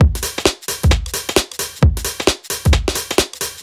Index of /musicradar/uk-garage-samples/132bpm Lines n Loops/Beats
GA_BeatD132-03.wav